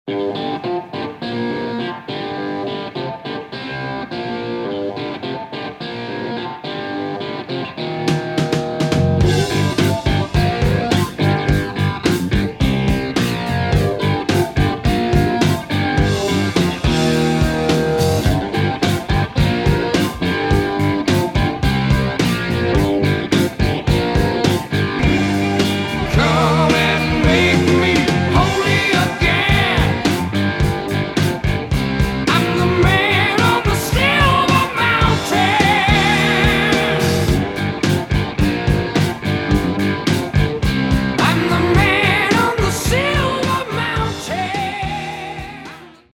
гитара
громкие
мощные
инструментальные
heavy Metal
хеви-метал
Жанры: Хеви-метал, Рок